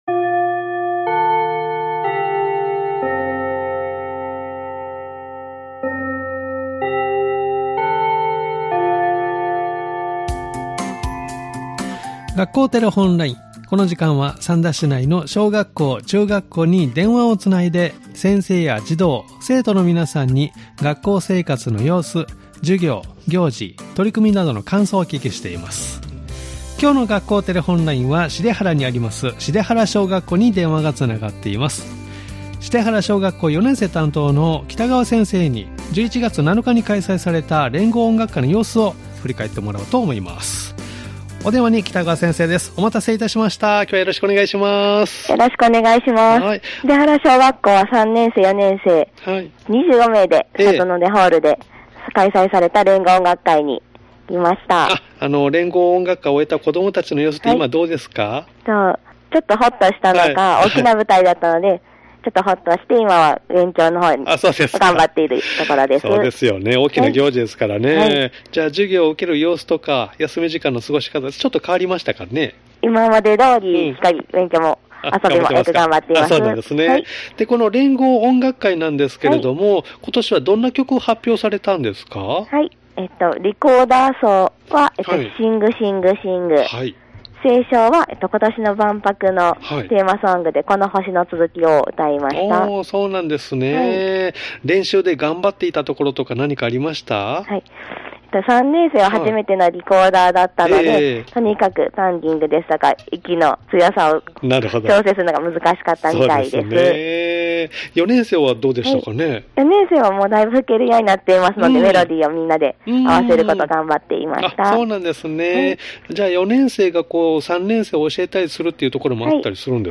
（再生ボタン▶を押すと番組が始まります） 「学校テレフォンライン」では三田市内の小学校、中学校に電話をつないで、先生や児童・生徒の皆さんに、学校生活の様子、授業や行事、取り組みなどの感想をお聞きしています！